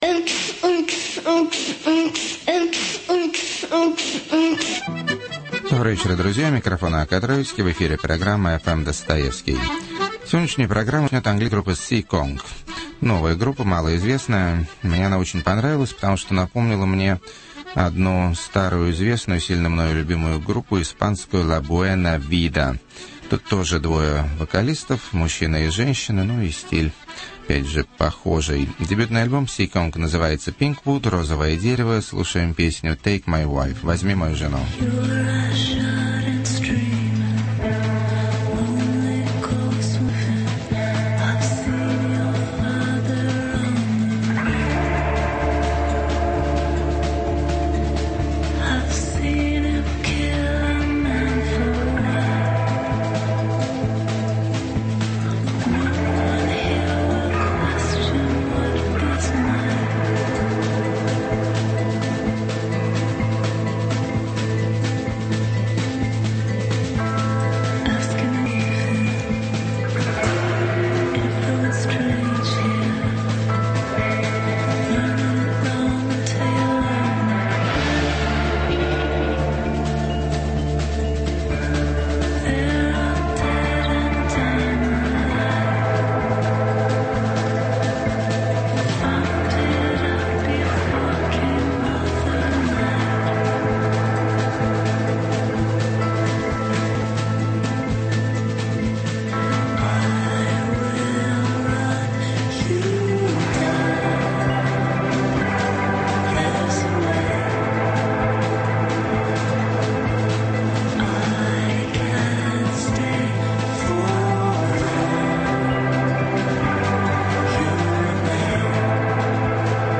Качество плохое из-за проблем с записью.